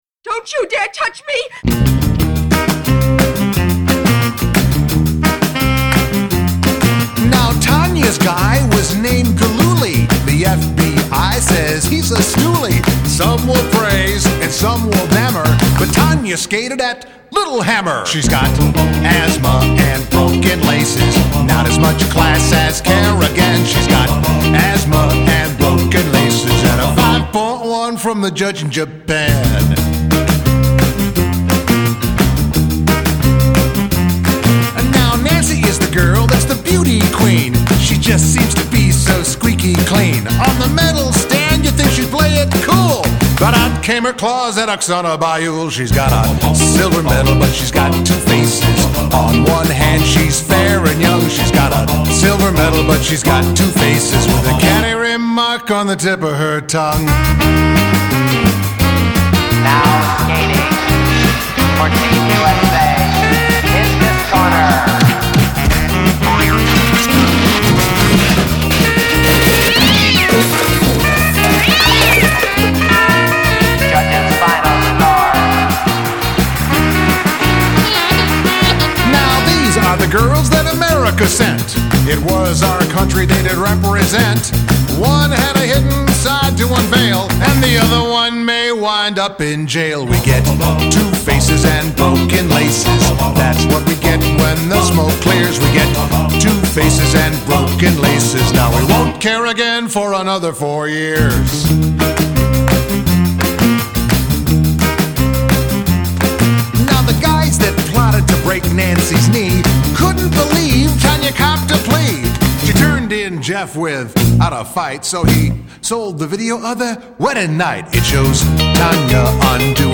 kerrigan pinataJohn Landecker was the morning man at WJMK at the time, and fronted the band Landecker & the Legends. They wrote and performed not just one, but two songs featuring this unbelievable story about one female figure skater plotting to kneecap another figure skater. One song was written before the Olympics, and the other one was written after the Olympics in Lillehammer.